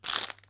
crunch.wav